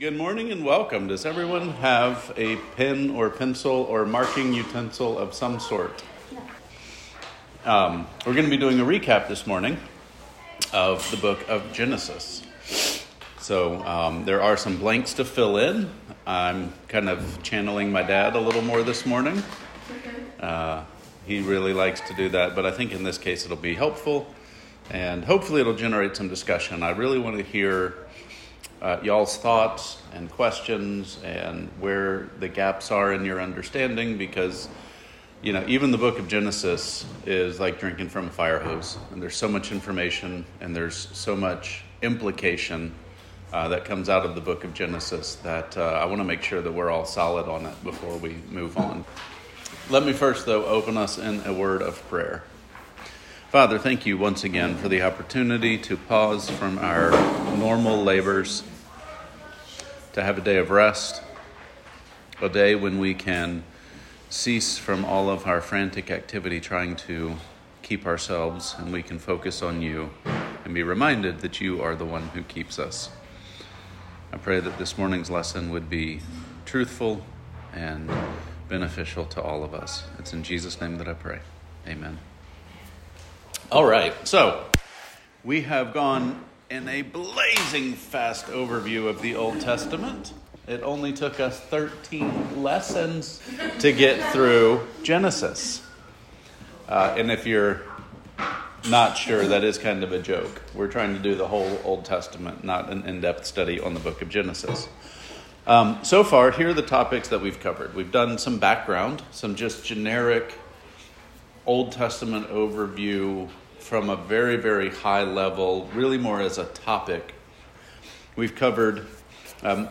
Old Testament Overview Seminar